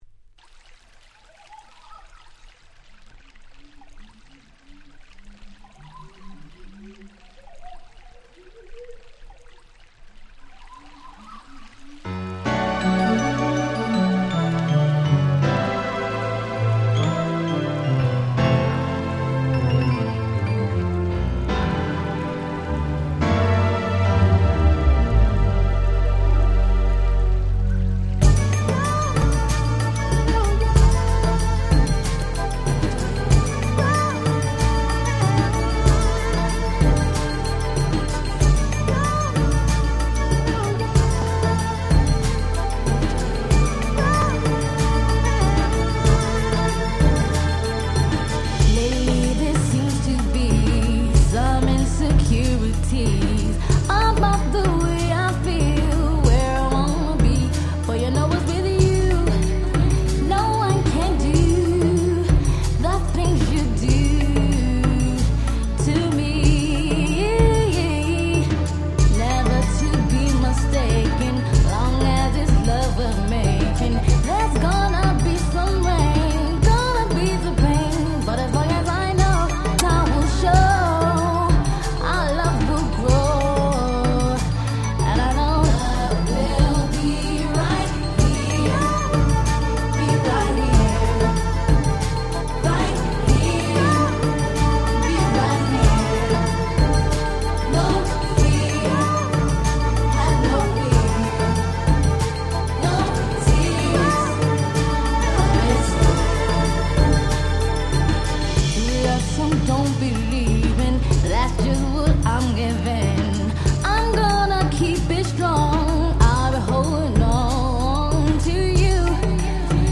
93' Super Hit R&B !!